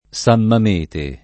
vai all'elenco alfabetico delle voci ingrandisci il carattere 100% rimpicciolisci il carattere stampa invia tramite posta elettronica codividi su Facebook San Mamete [ S am mam % te ] o San Mamette [ S am mam % tte ] top.